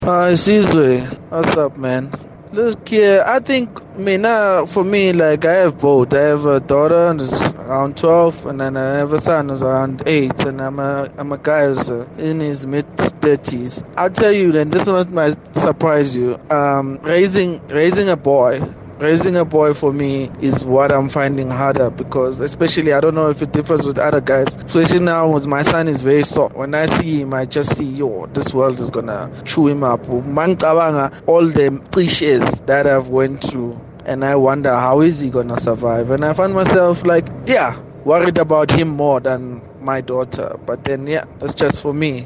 Kaya Drive listeners shared their experience: